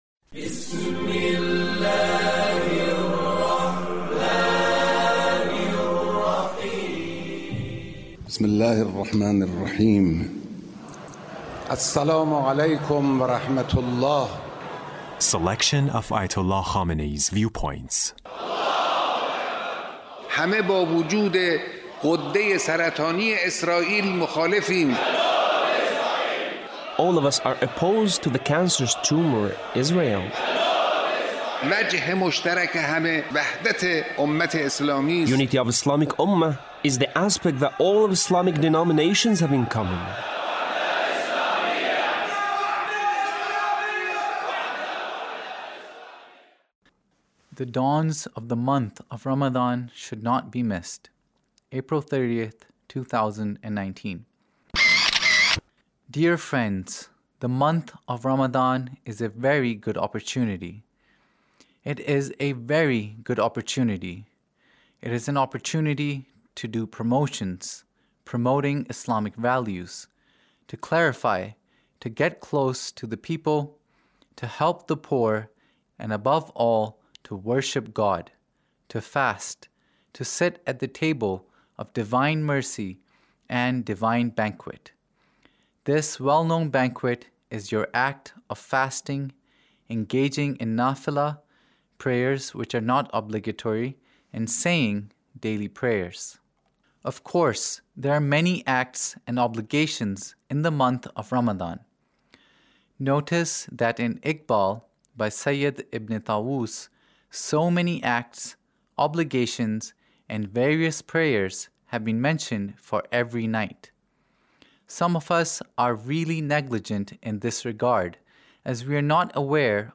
Leader's Speech (1909)